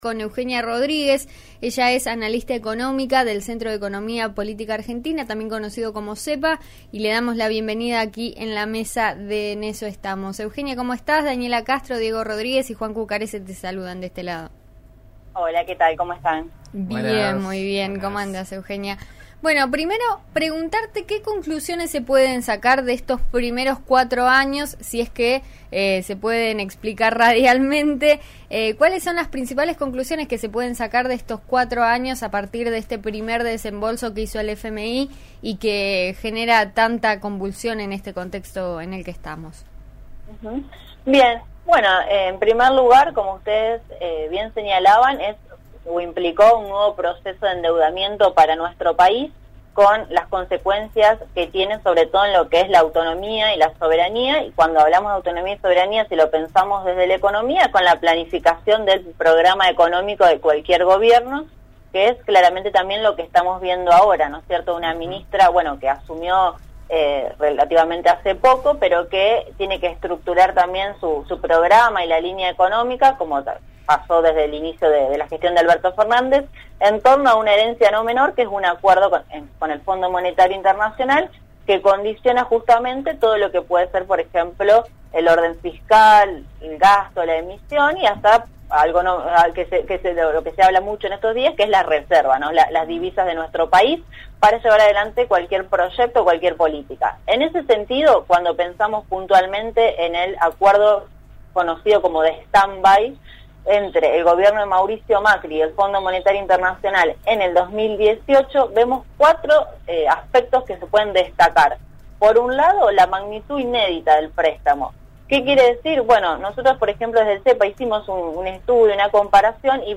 En eso estamos de RN Radio (105.7 en Roca y 90.9 en Neuquén)